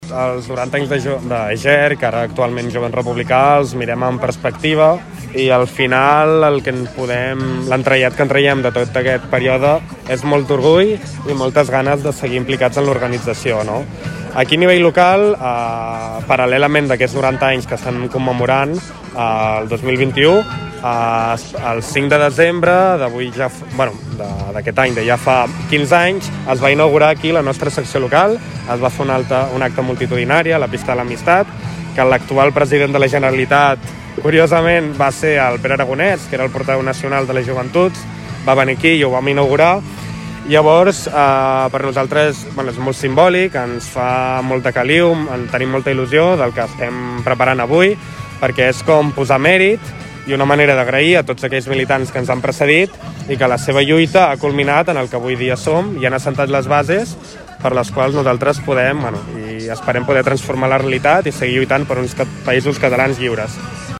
Es commemora el 90è aniversari de Jovent Republicà en un acte a la plaça 1 d’octubre